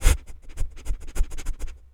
pgs/Assets/Audio/Animal_Impersonations/rabbit_breathing_01.wav at master
rabbit_breathing_01.wav